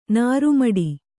♪ nāru maḍi